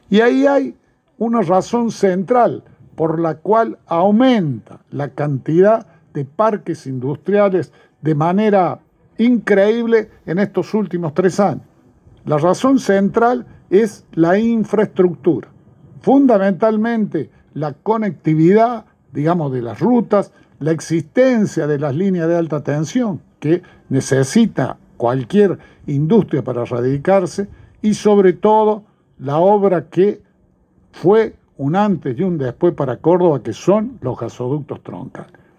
Audio del gobernador Schiaretti durante el acto